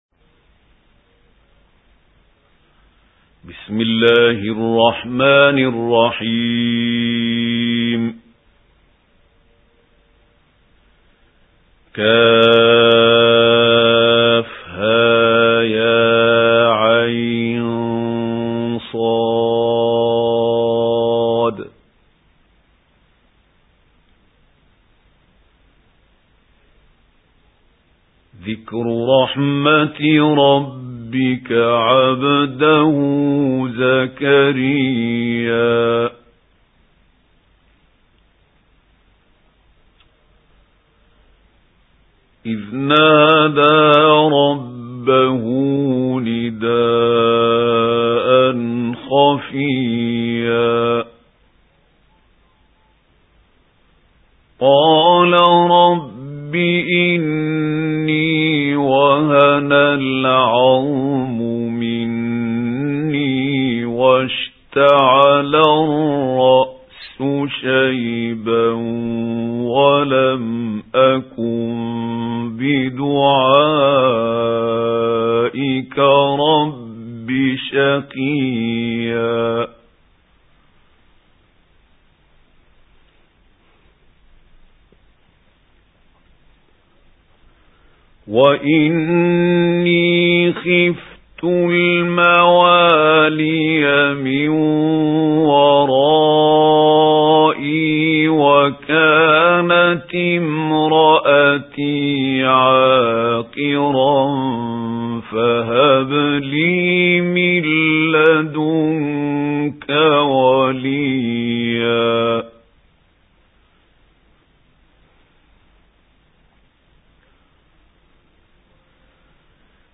سُورَةُ مَرۡيَمَ بصوت الشيخ محمود خليل الحصري